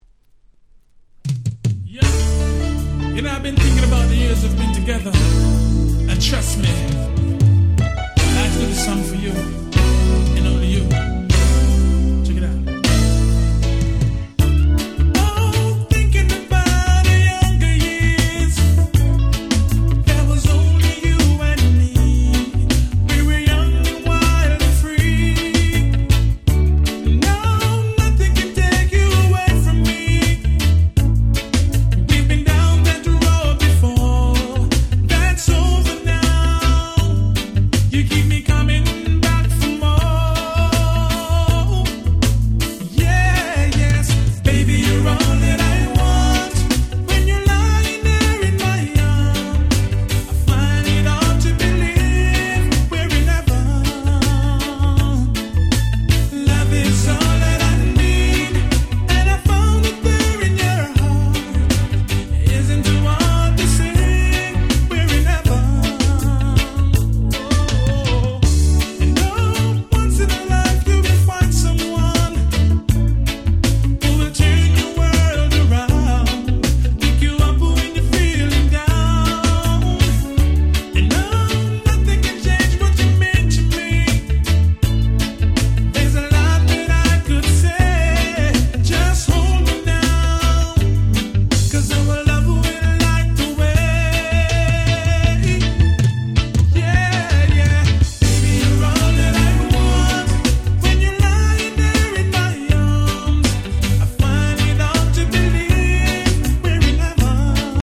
06' Super Nice Reggae Cover !!